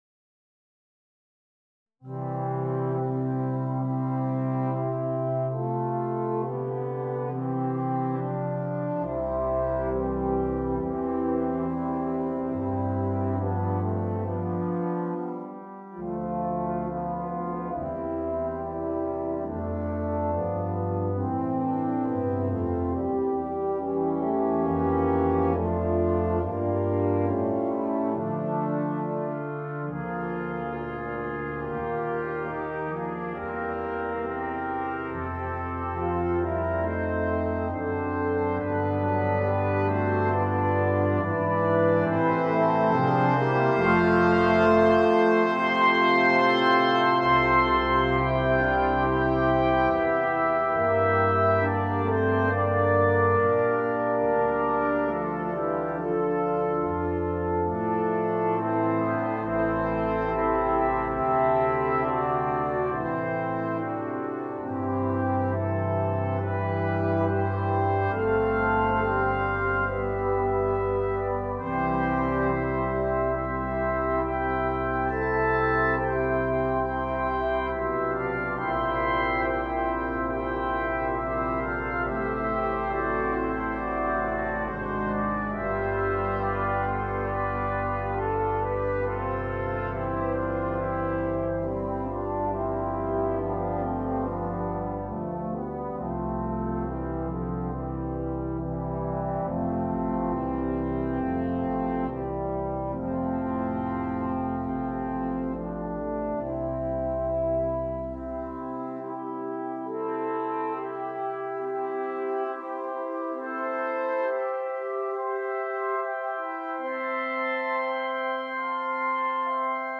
Noten für Blechbläserensemble
Genre Klassik